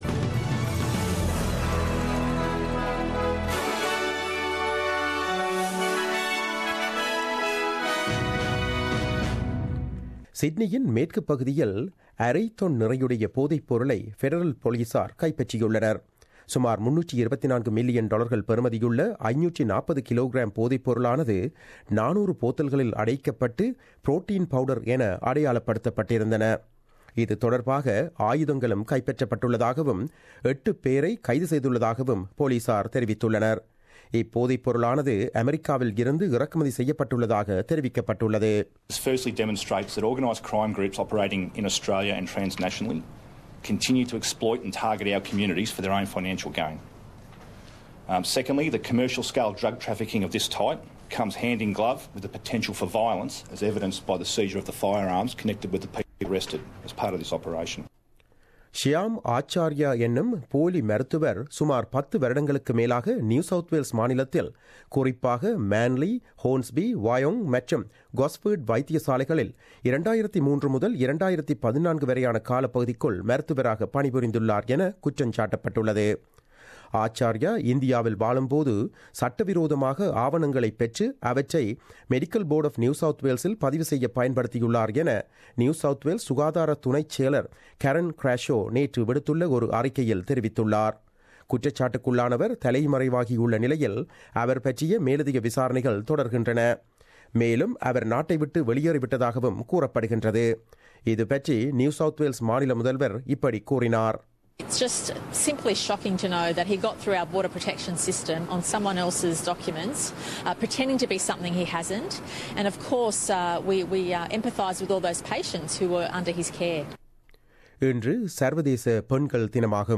The news bulletin aired on 08 March 2017 at 8pm.